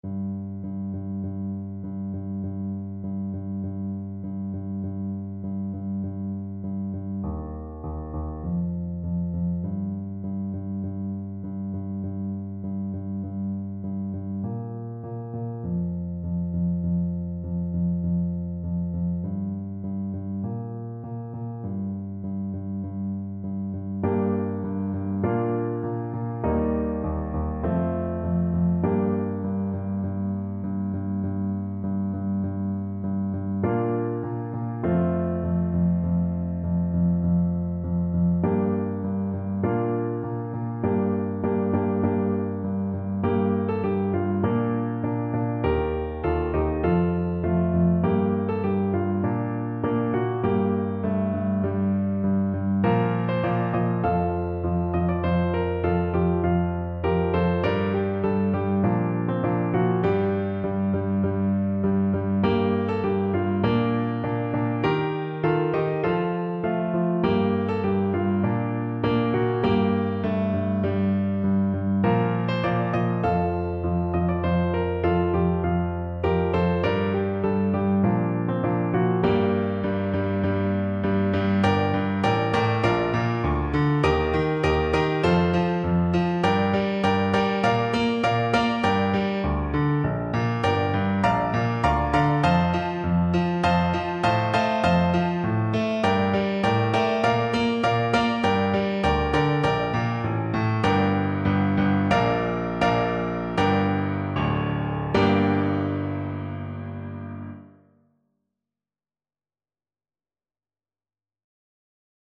Play (or use space bar on your keyboard) Pause Music Playalong - Piano Accompaniment Playalong Band Accompaniment not yet available transpose reset tempo print settings full screen
Trumpet
Traditional Music of unknown author.
4/4 (View more 4/4 Music)
G minor (Sounding Pitch) A minor (Trumpet in Bb) (View more G minor Music for Trumpet )
Moderato =c.100